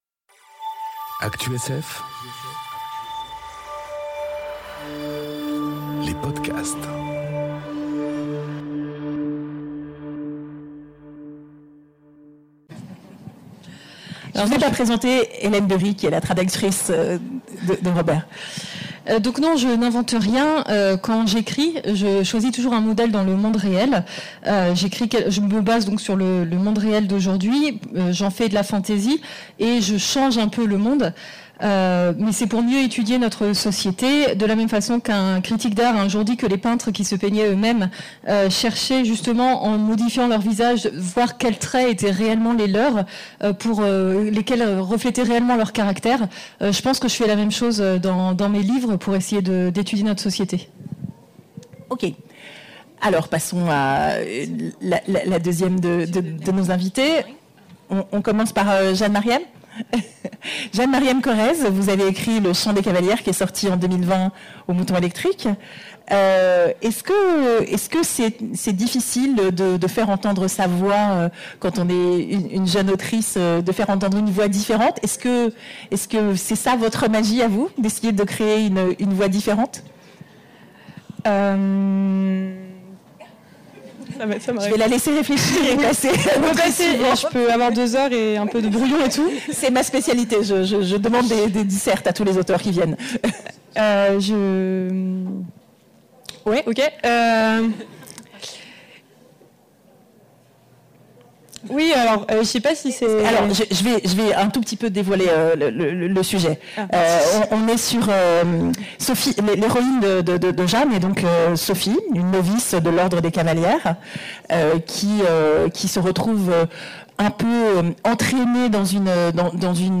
Vendredi, 14h aux Imaginales 2021... que du beau monde dans ce Magic Mirror pour parler de magie.